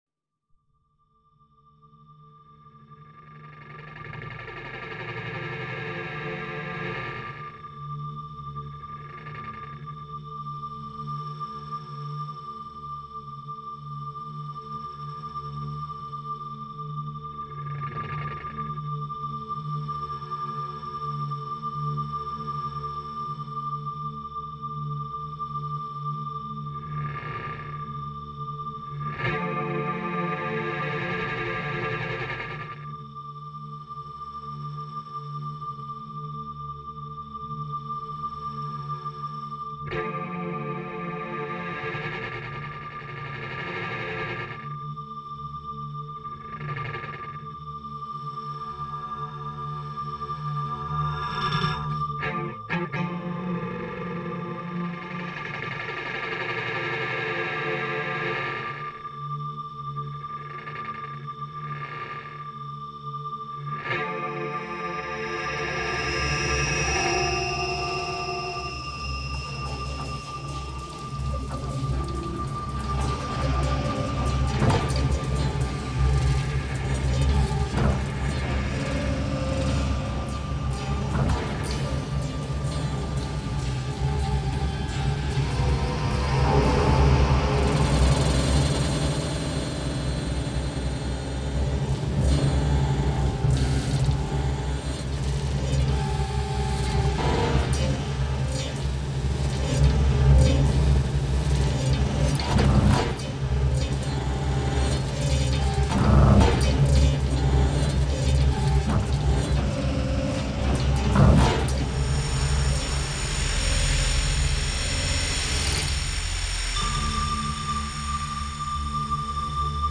Slow motion through intangible and blurred shapes.